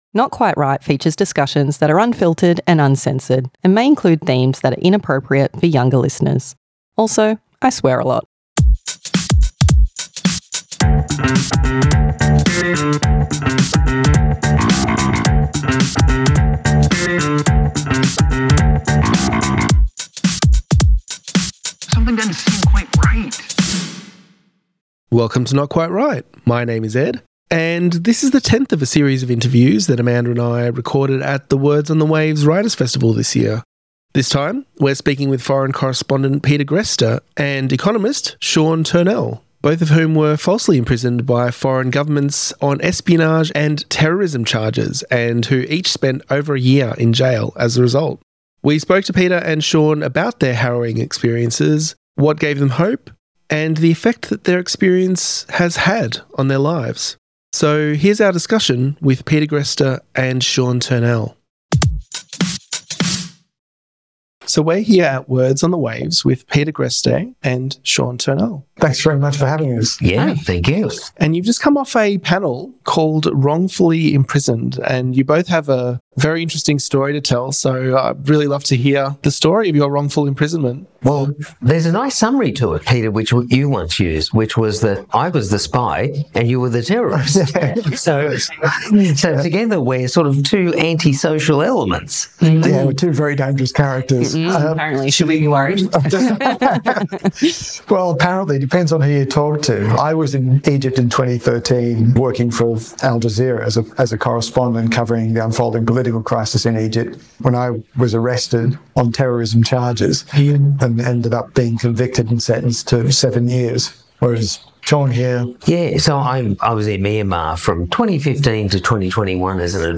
Interview with Peter Greste & Sean Turnell at Words on the Waves 2025 - Not Quite Write Podcast
interview-with-peter-greste-sean-turnell-at-words-on-the-waves-2025.mp3